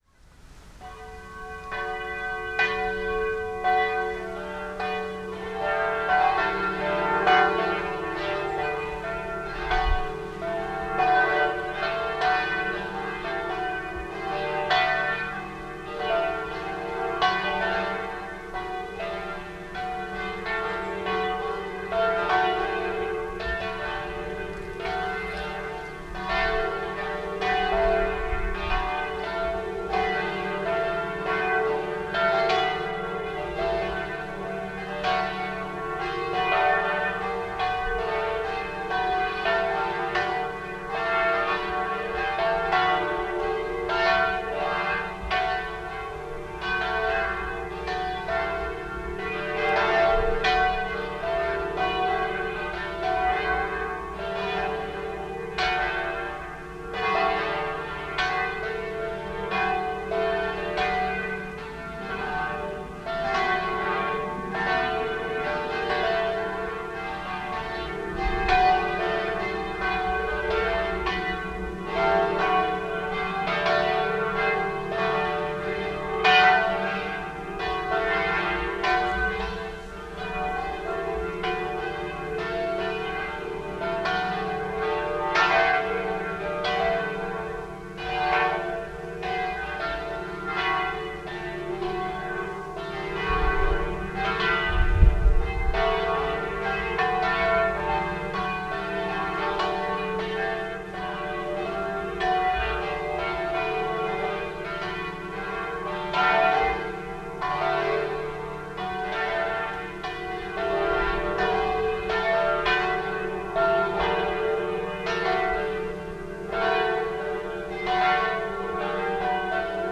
Sound example: The sound of bells being dispersed in the wind.
PerceBellsInWInd.aif